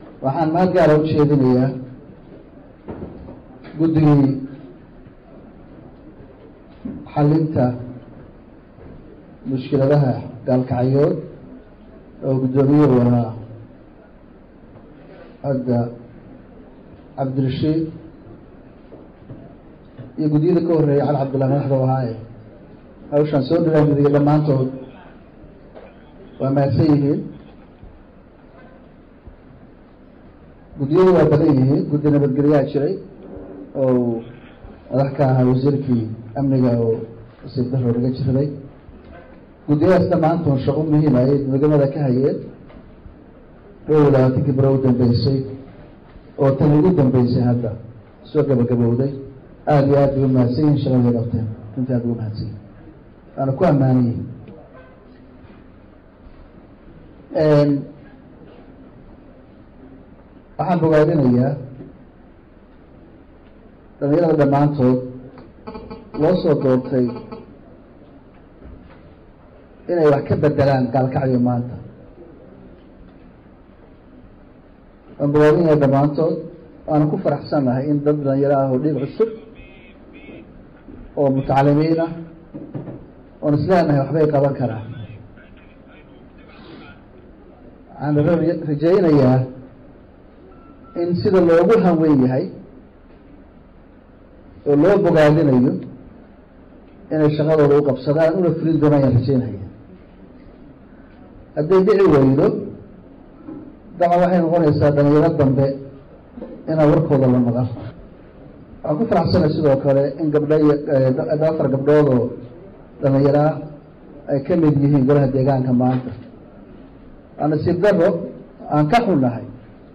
Dhagayso: Madaxweyne Gaas oo khudbad u jeediyey shacabka Mudug
24 July 2016 (Puntlandes) Madaxweynaha dowladda Puntland Cabdiweli Maxamed Cali Gaas, ayaa khudbad xasaasi ah u jeediyey shacabka Gobolka Mudug gaar ahaan kan magaalada Galkacyo xilli uu ka qaybgelayey doorashadii maamulka degmada Galkacyo, waxaana uu kahadlay arrimo badan.
Madaxweyne Gaas ayaa cod dheer shacabka Gaalkacyo ugu sheegay inay iminka wixii ka dambeeya isku tashtaan oo ay ka shaqeeyaan Amnigooga iyo Horumarkooda.